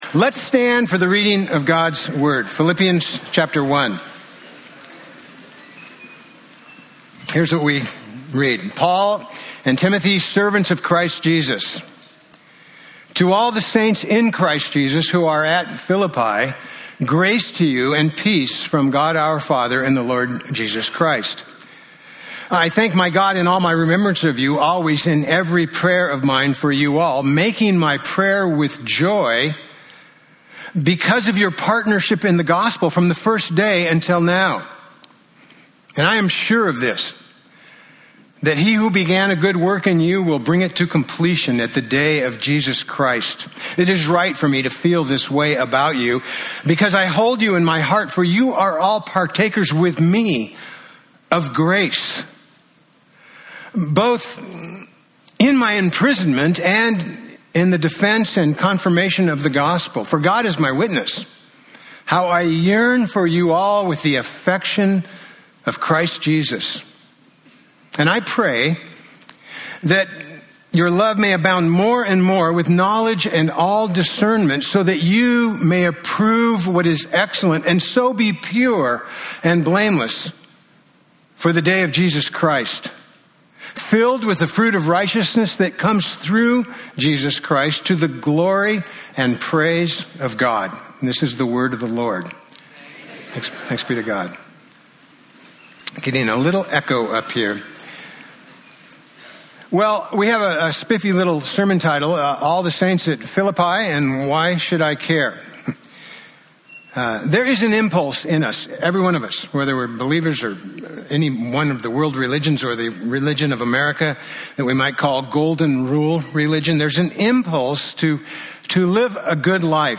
Preacher
Philippians 1:1-2 Service Type: Sunday Topics